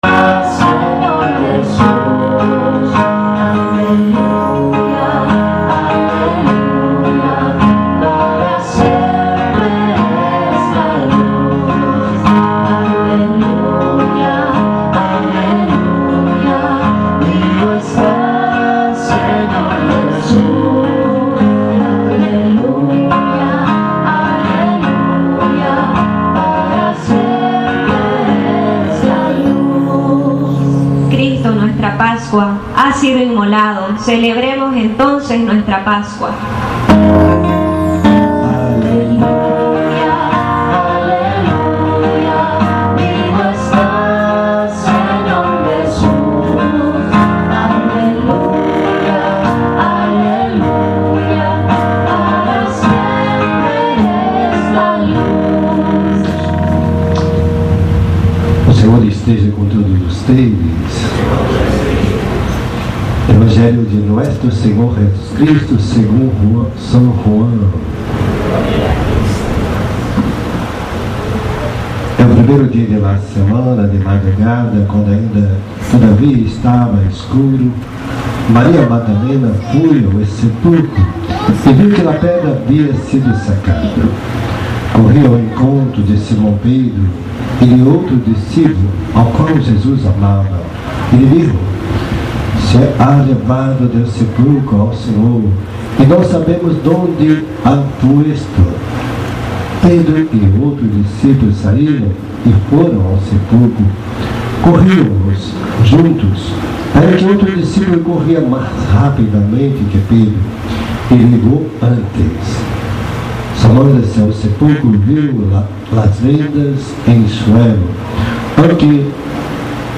Homilía Pascual